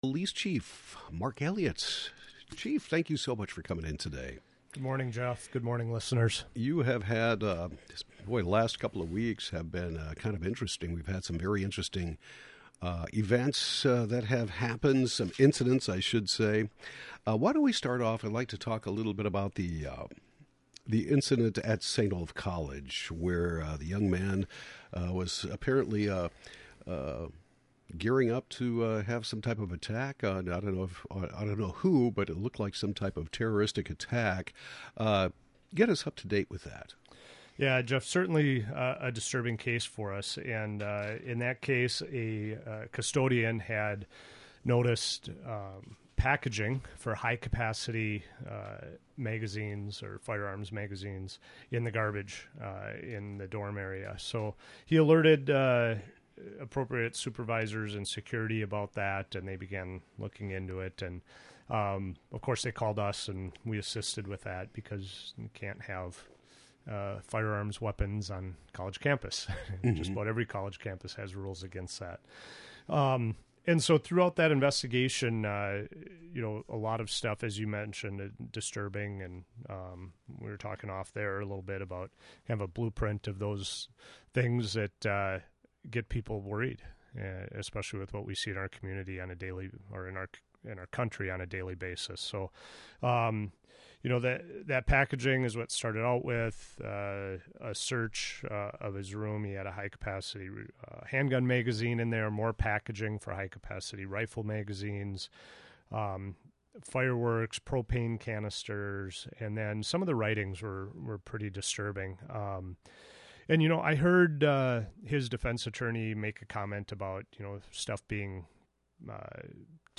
Northfield Police Chief Mark Elliott discusses the recent incident involving a St. Olaf student who was allegedly planning an attack, talks about a high speed chase that ended in Northfield this week and resulted in an injured officer, and more.